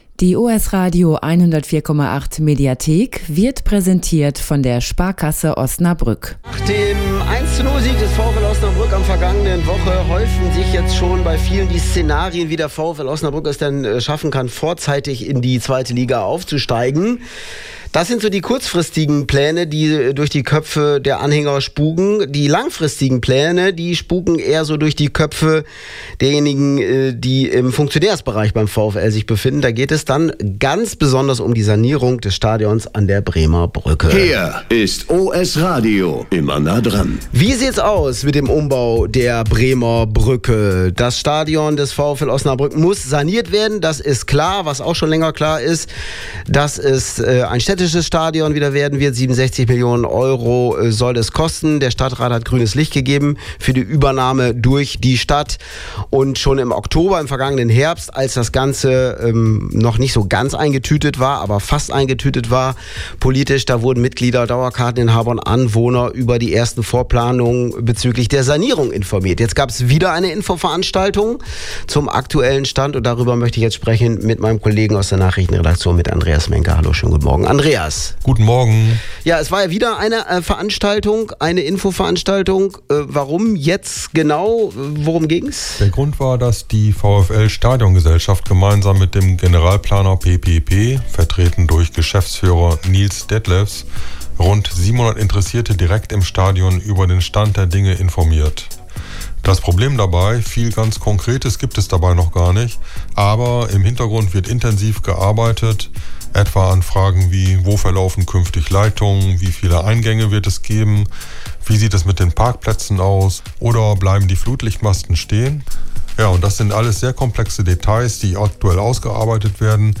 Bei einer Infoveranstaltung am Donnerstagabend, 16. April wurden rund 700 Interessierte über den aktuellen Stand informiert. Klar ist: Hinter den Kulissen wird intensiv geplant, doch zentrale Fragen zu Bauablauf, Zeitplan und Details bleiben offen. Auch Themen wie Flutlichtmasten, Ticketregelungen und mögliche Ausweichstadien sorgen weiterhin für Diskussionsstoff. Mehr dazu in folgendem Mitschnitt: